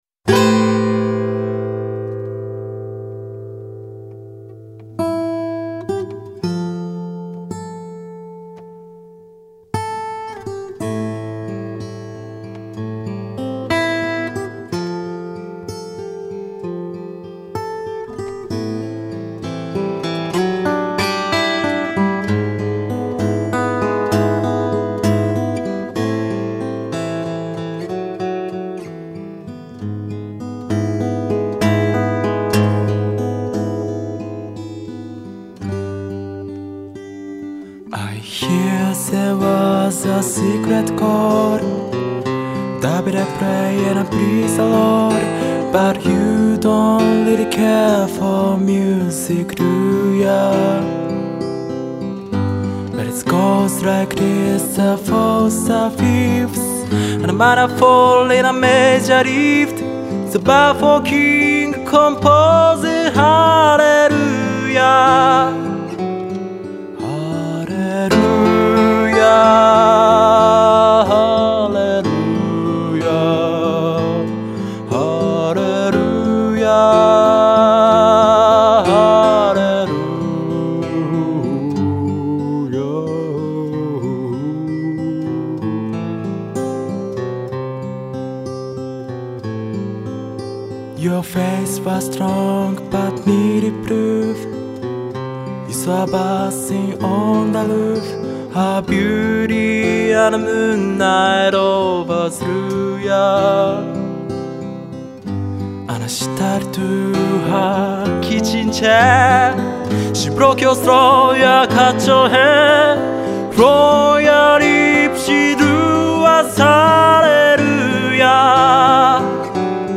彼女いわく、彼女と同じように小さなライブハウスで演奏を始める時、必ずそのアレルヤを歌ってから彼の時間が始まるそうです。